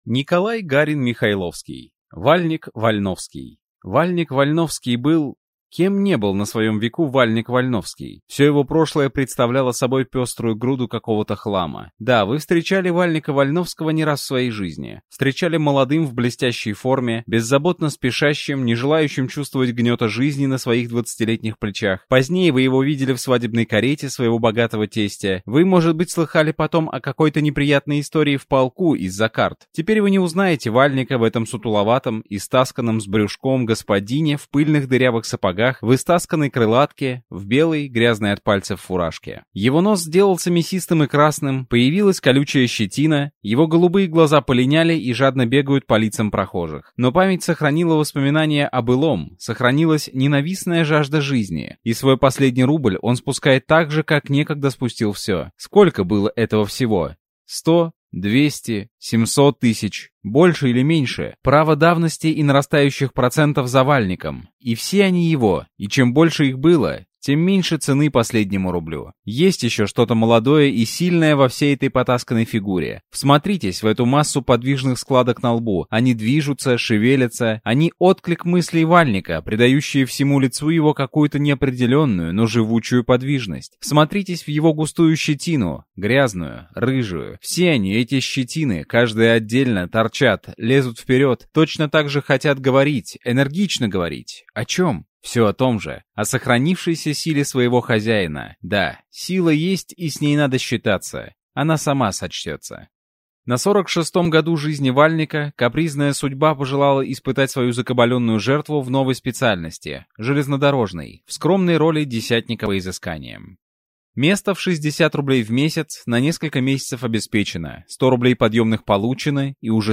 Аудиокнига Вальнек-Вальновский | Библиотека аудиокниг